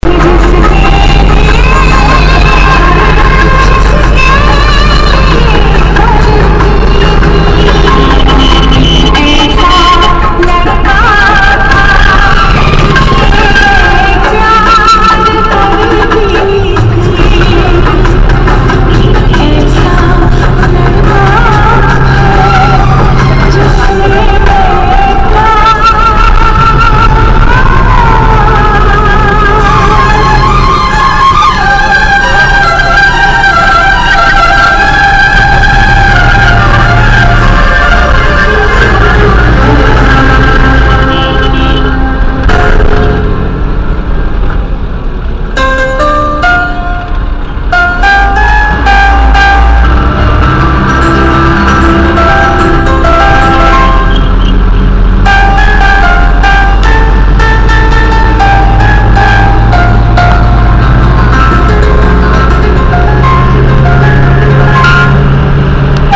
Au rythme du klaxon enjoué,
La musique crache a tue-tête,
ambiance_bus.MP3